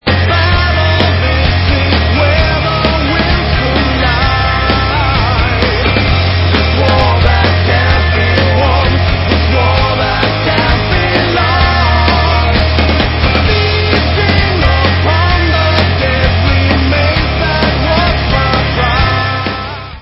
Hard Rock